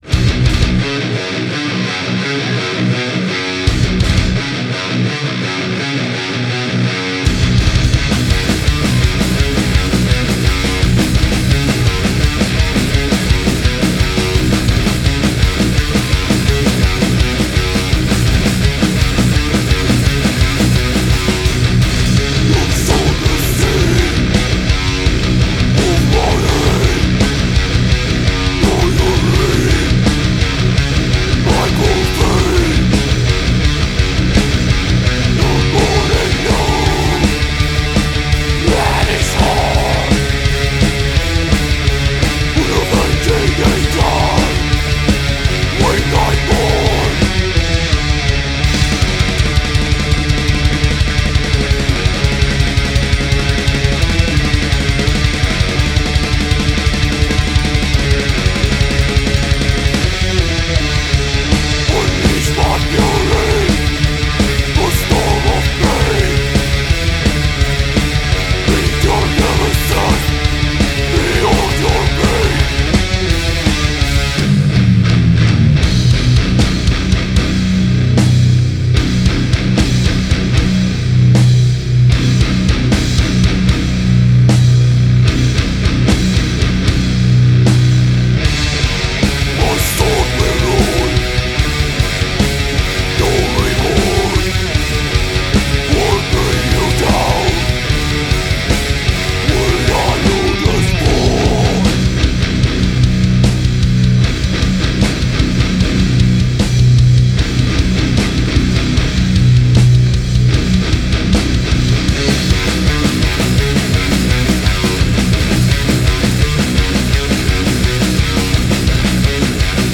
Five tracks of aggressive & unrelenting metal.